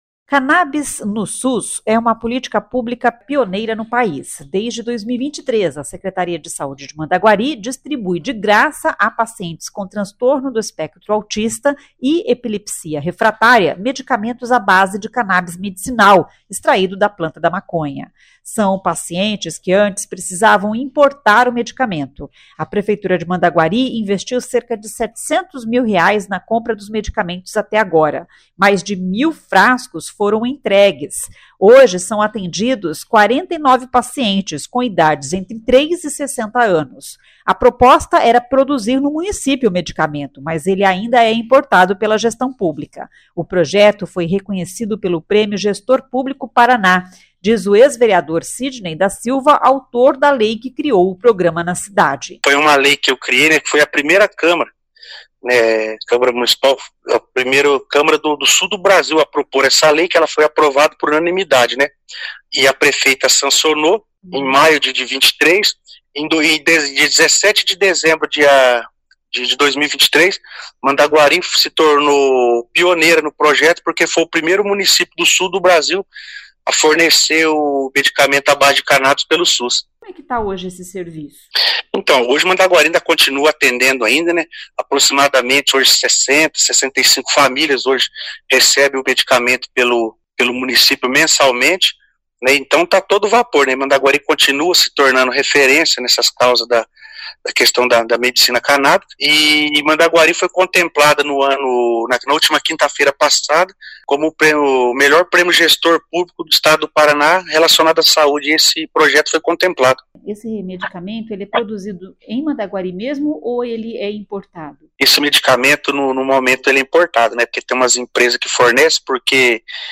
O projeto foi reconhecido pelo Prêmio Gestor Público Paraná, diz o ex-vereador Sidney da Silva, autor da lei que criou o programa na cidade.